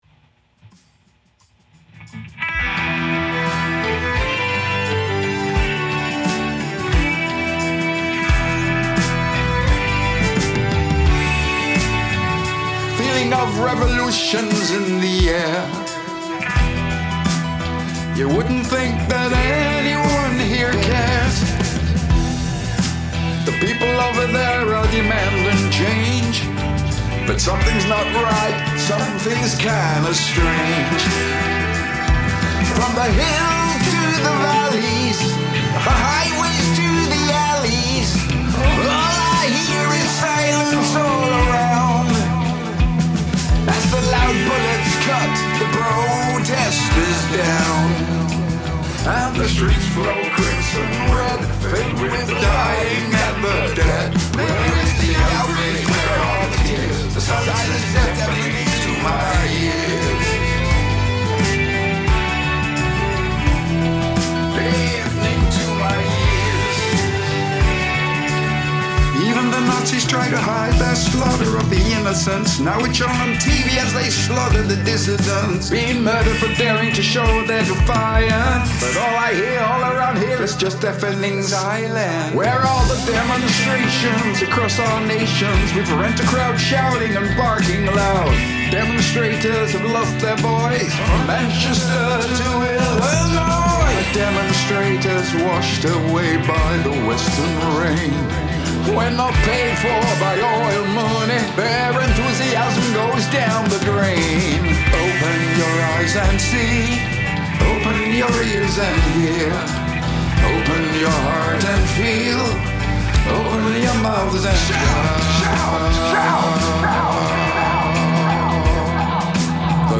visceral, politically charged anthem